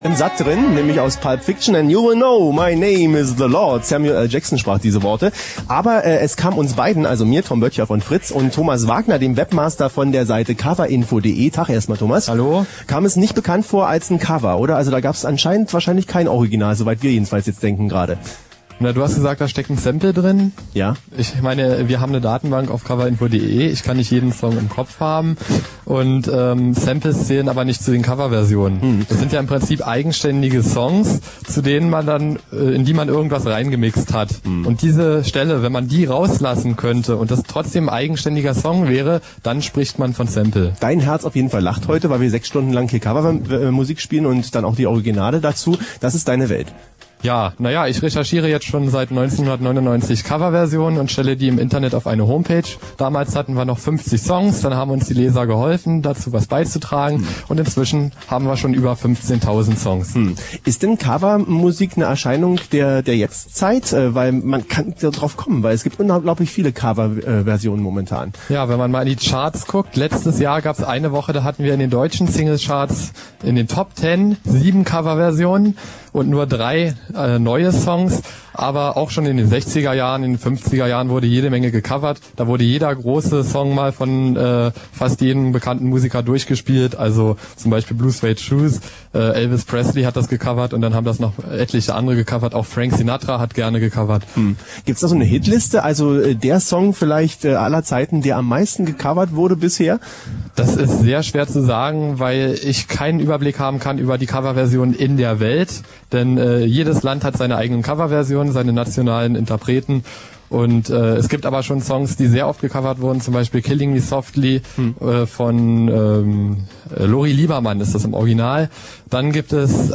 Radio Fritz sendete am Karfreitag von 12 bis 18 Uhr ein "Fritz Extra".